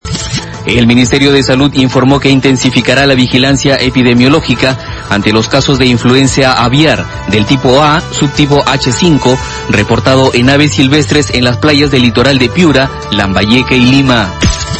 Titulares